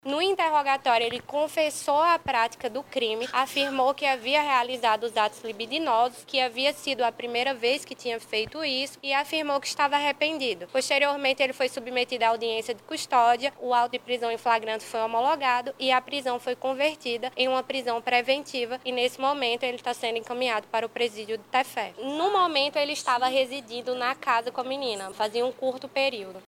A delegada disse, ainda, durante uma entrevista coletiva, que o homem confessou o crime durante depoimento na delegacia.